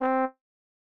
Trumpet.wav